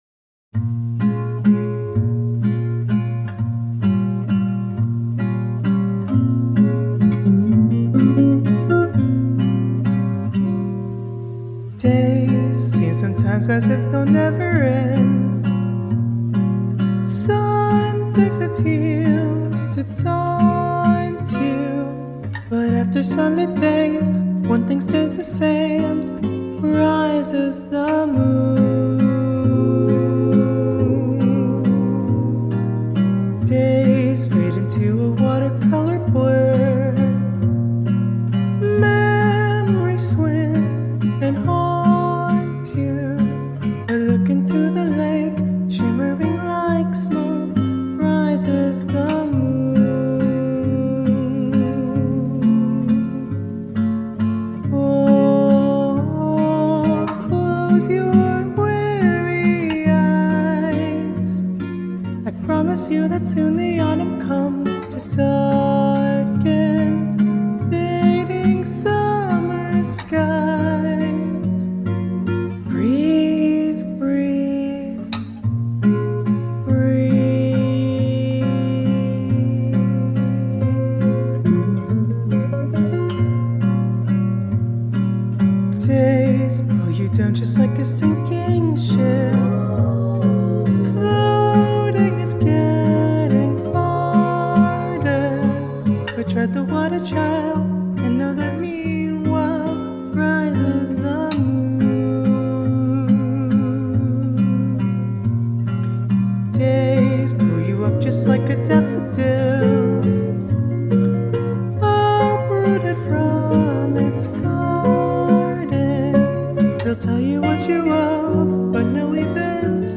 Music Covers